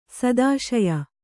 ♪ sadāśaya